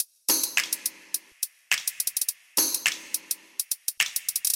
Tag: 105 bpm House Loops Drum Loops 787.67 KB wav Key : Unknown